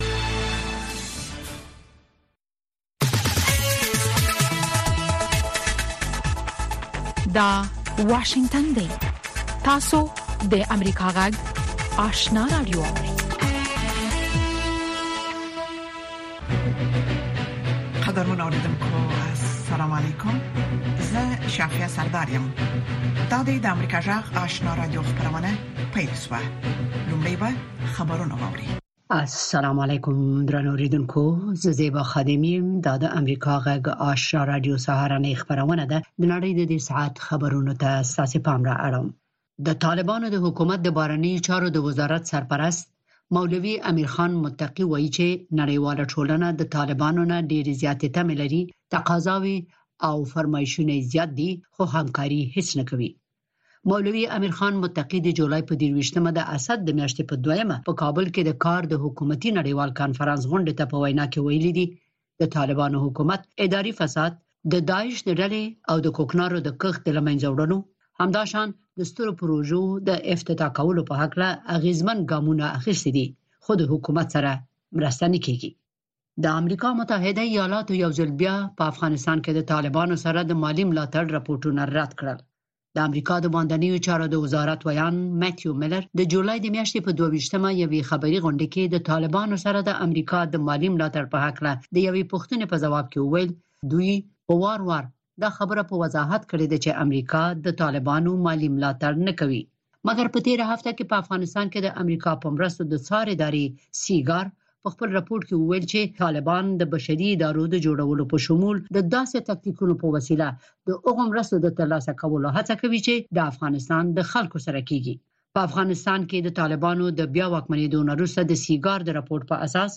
دویمه سهارنۍ خبري خپرونه